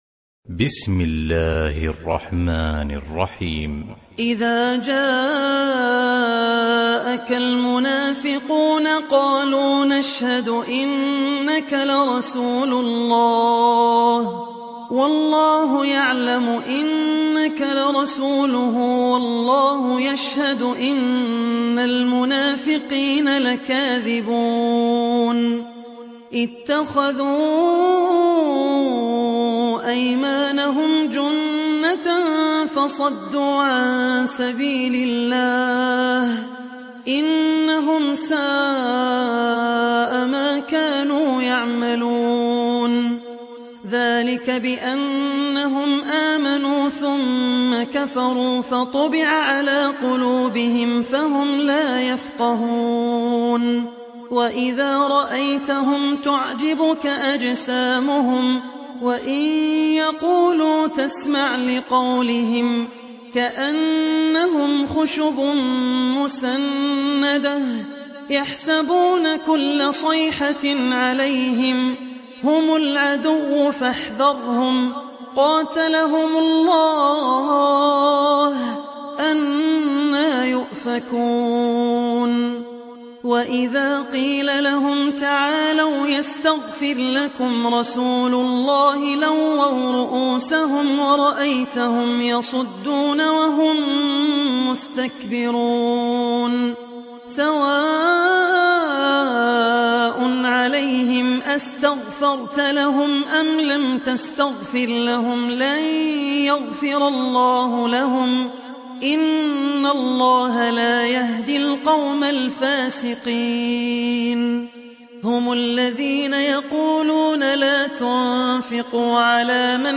تحميل سورة المنافقون mp3 بصوت عبد الرحمن العوسي برواية حفص عن عاصم, تحميل استماع القرآن الكريم على الجوال mp3 كاملا بروابط مباشرة وسريعة